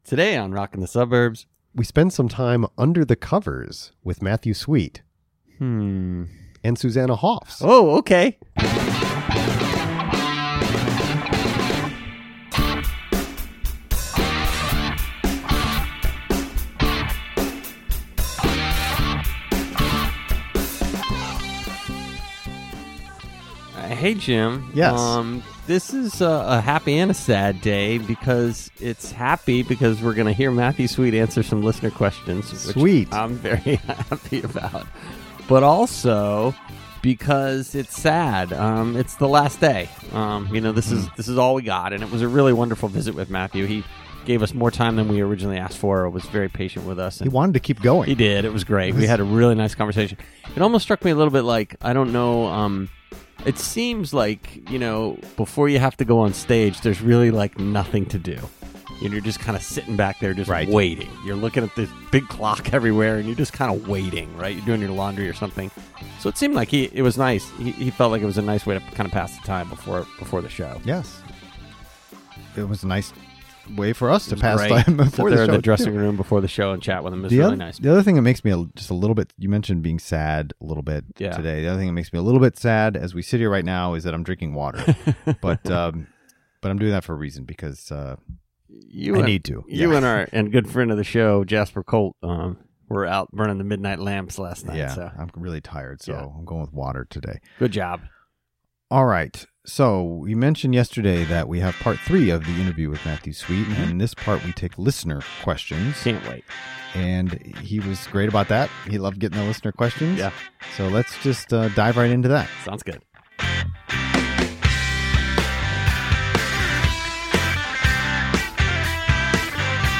Interview: Matthew Sweet, Part 3